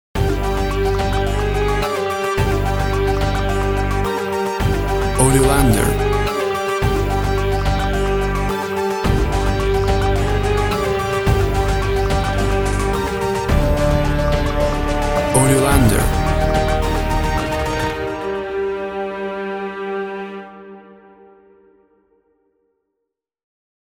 Tempo (BPM) 106